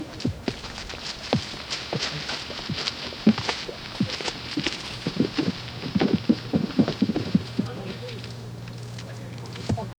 Quiet, emotional forest ambience with muffled wind and distant bird calls. Soft dripping sound like magical tears hitting the moss.